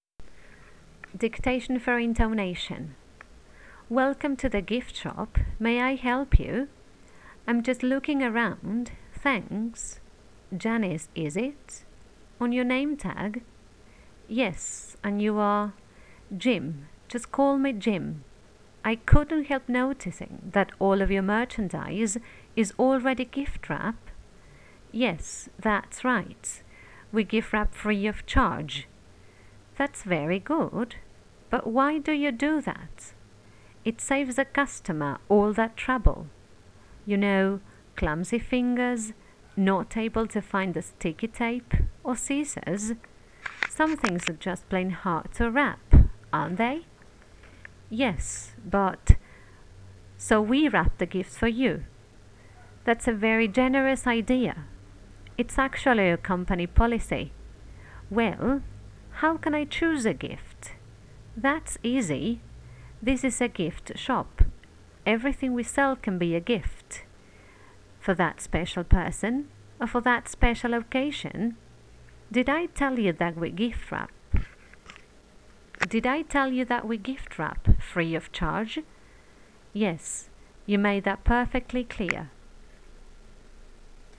The Gift dictation for intonation.wav